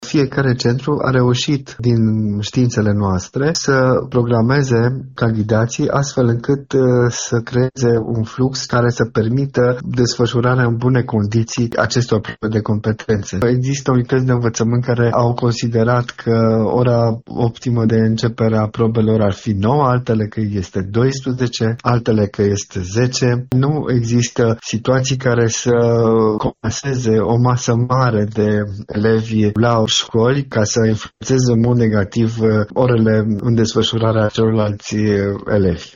Cele mai multe școli organizează dimineață examenul de bacalaureat, însă ora de începere diferă, spune președintele comisiei județene de bacalaureat Timiș, inspectorul școlar general adjunct Cosmin Hogea.